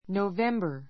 November 小 A1 nouvémbə r ノウ ヴェ ンバ 名詞 11月 ⦣ Nov.